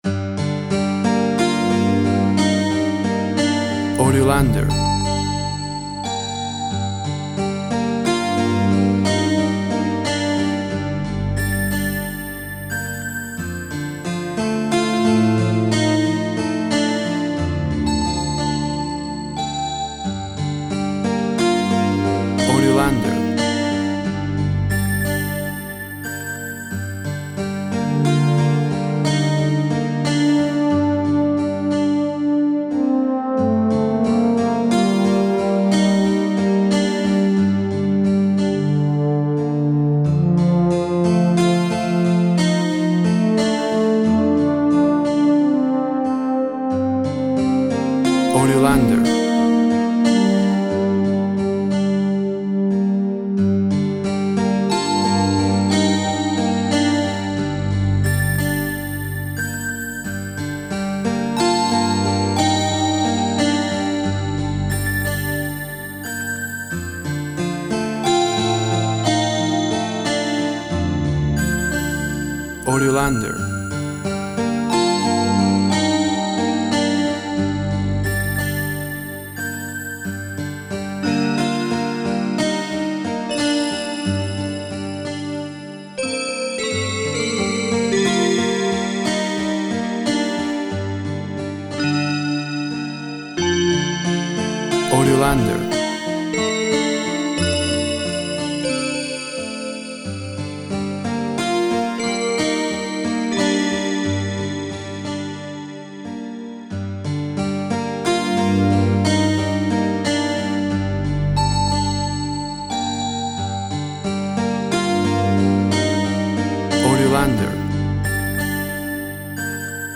Tempo (BPM) 88